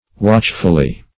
[1913 Webster] -- Watch"ful*ly, adv. --